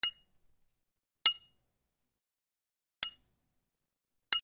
金属 ぶつかる
/ M｜他分類 / L01 ｜小道具 / 金属